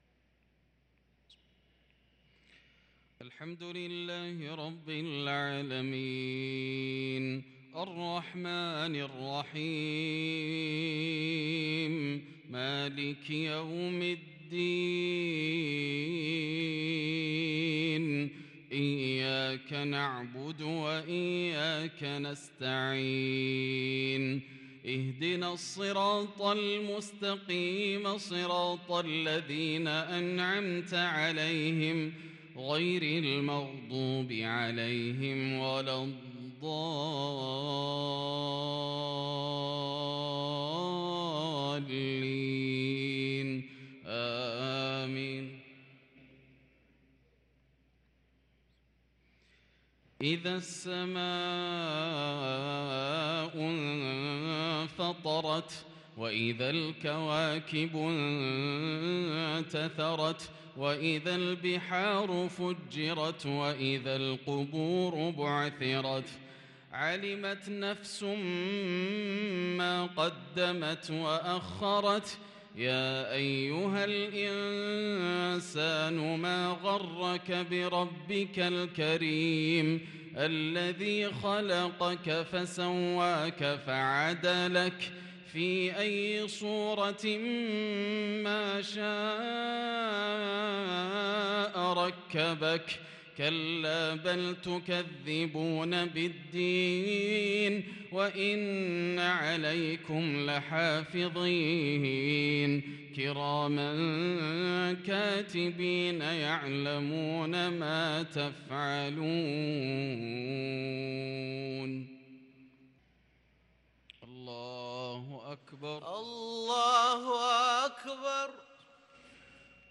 صلاة المغرب للقارئ ياسر الدوسري 14 جمادي الأول 1444 هـ
تِلَاوَات الْحَرَمَيْن .